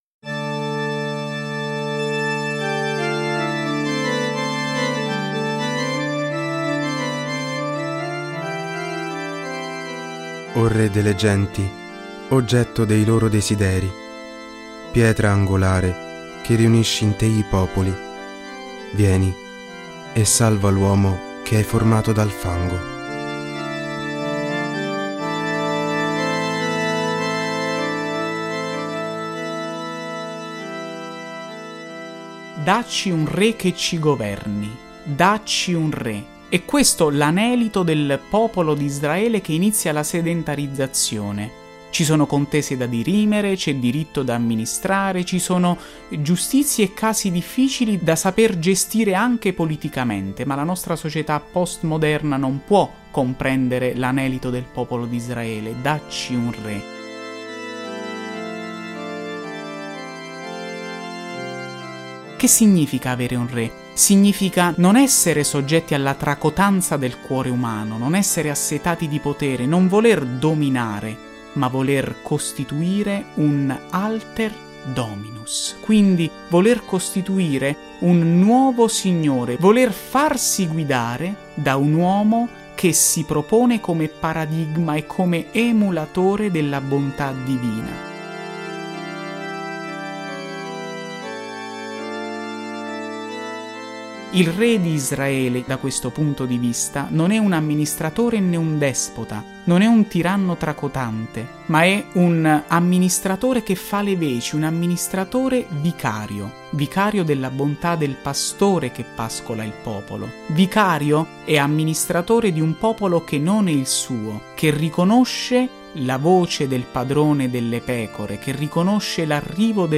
Le esecuzioni delle Antifone O dell'"Ensemble dei Fiorentini"
Dalle antifone maggiori dell’Avvento – O Rex Gentium